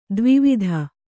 ♪ dvi vidha